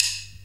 D2 SDRIM05-L.wav